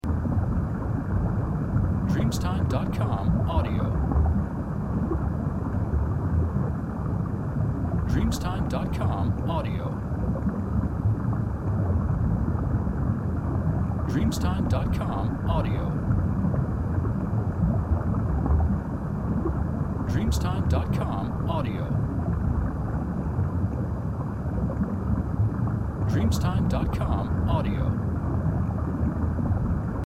Korallenriff und tropische Fische